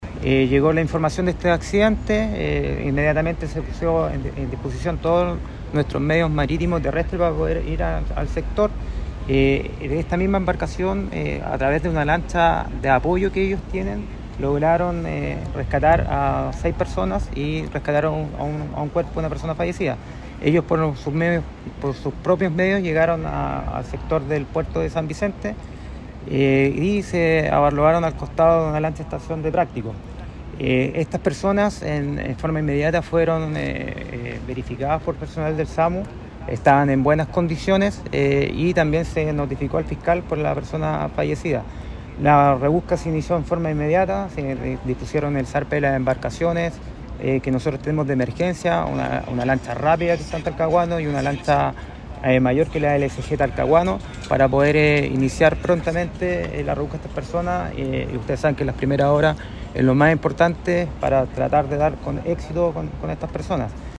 El gobernador marítimo (s), capitán Cristián Díaz, informó que el naufragio quedó registrado cerca de las 23.40 horas. El caso trata de una barcaza menor, denominada Magdalena II, que salió del Puerto San Vicente con 10 tripulantes.